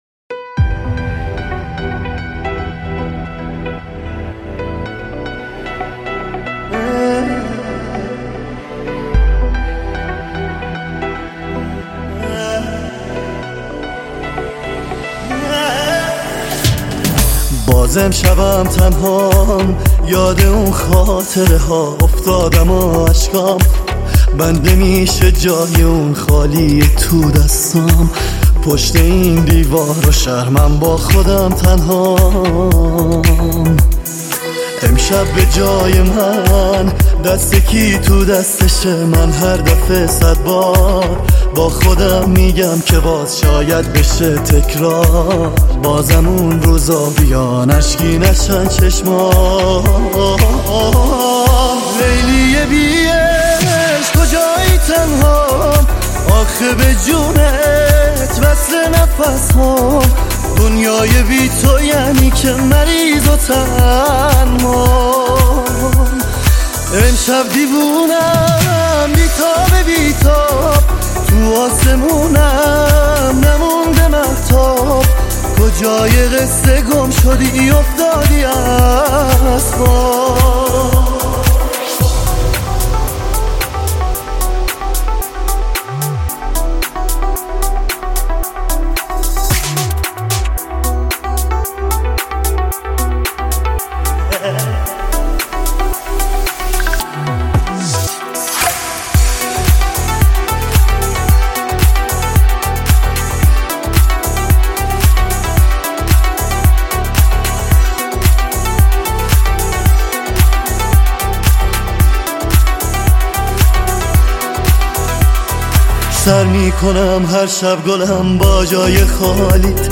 دانلود آهنگ شاد با کیفیت ۱۲۸ MP3 ۴ MB